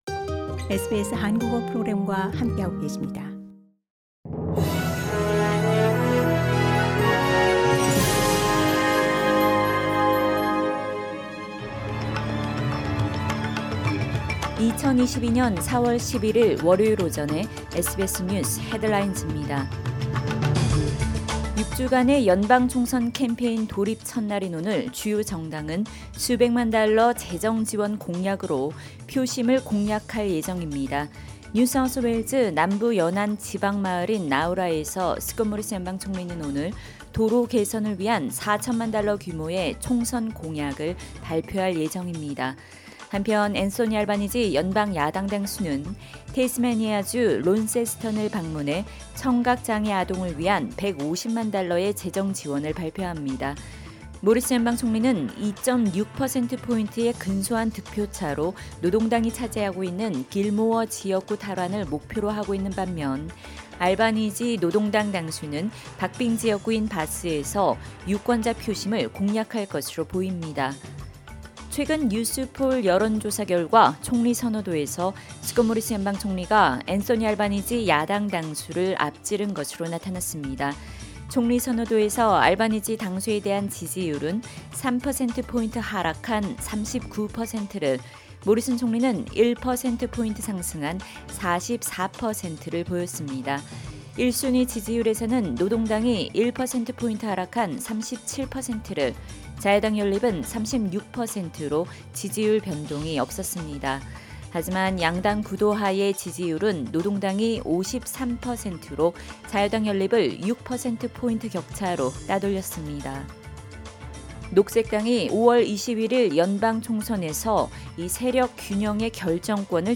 2022년 4월 11일 월요일 오전 SBS 뉴스 헤드라인즈입니다.